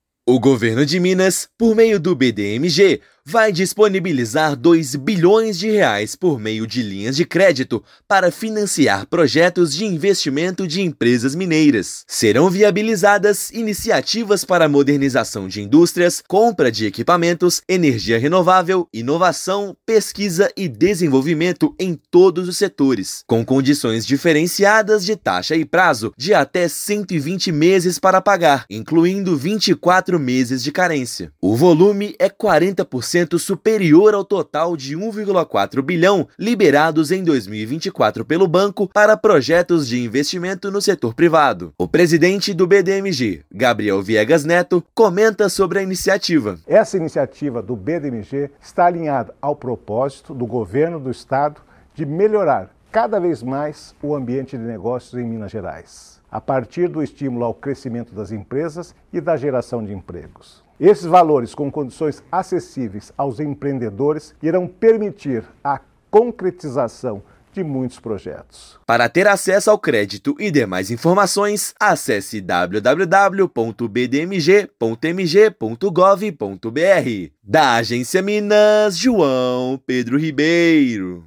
BDMG anuncia montante com taxas e condições diferenciadas para viabilizar modernização, compra de equipamentos, energia renovável e inovação. Ouça matéria de rádio.